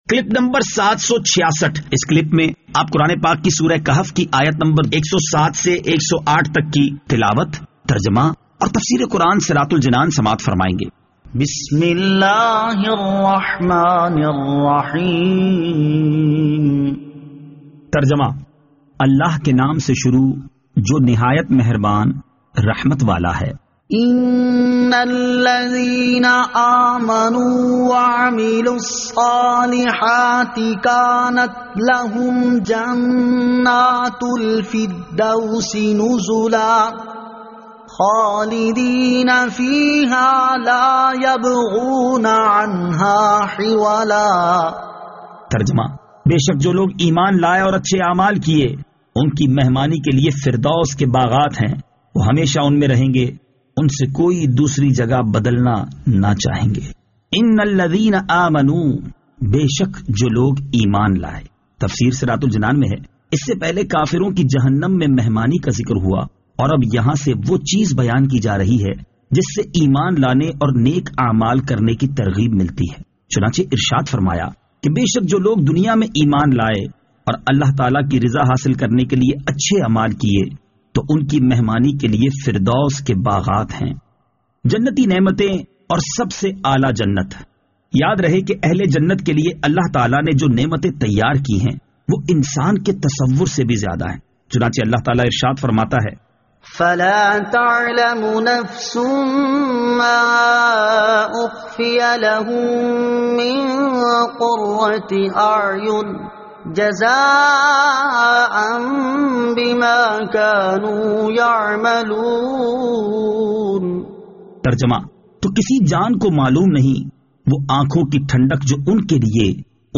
Surah Al-Kahf Ayat 107 To 108 Tilawat , Tarjama , Tafseer